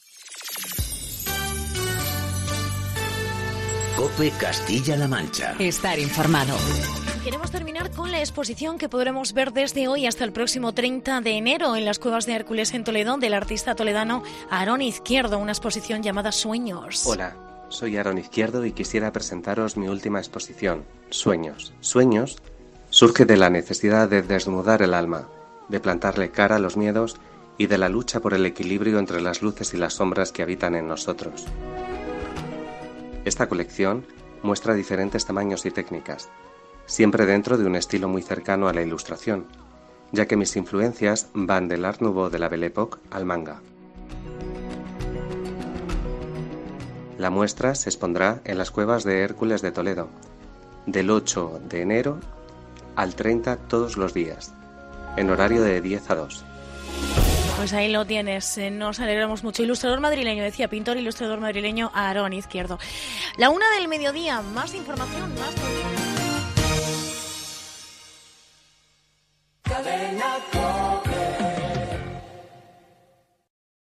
Entrevista-de-Radio-Cope.-Cope-Castilla-la-Mancha..mp3